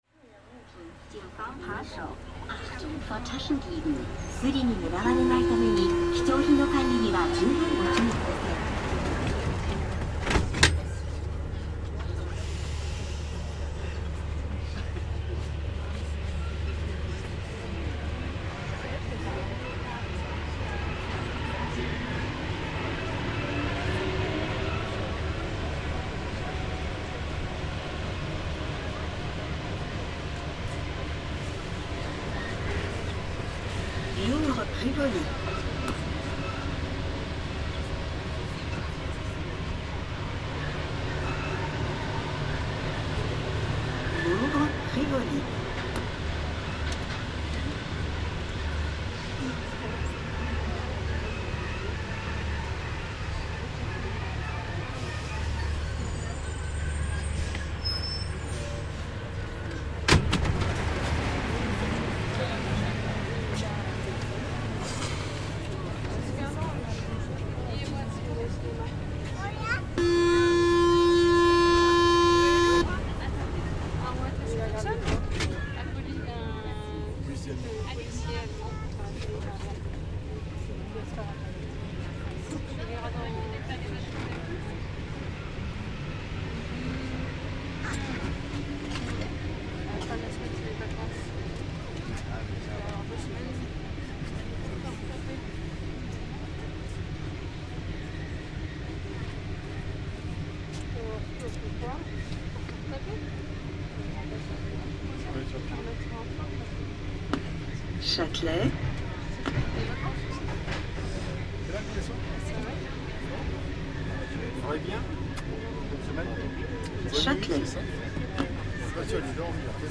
Aboard the Paris RER at Louvre
A ride on the RER in Paris from the world-famous Louvre - recorded by Cities and Memory.